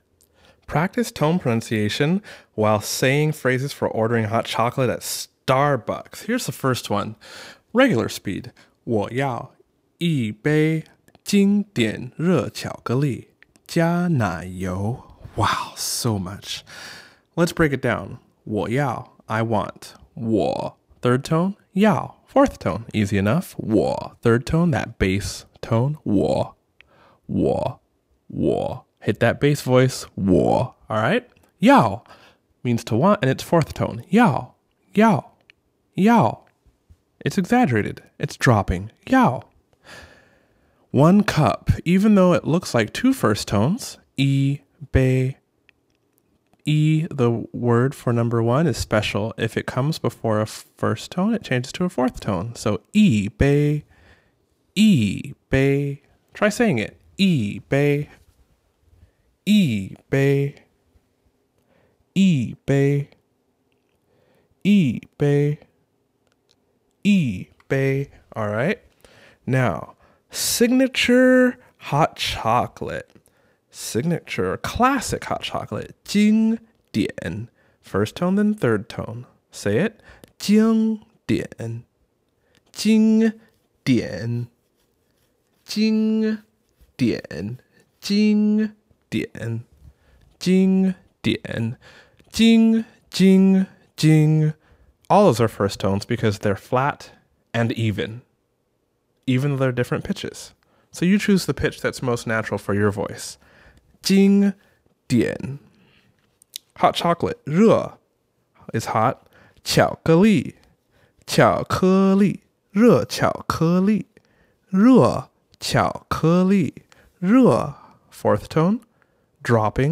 But, why does it sound like the fourth tone when I say it? That's because yī changes to yì when a first tone follows it.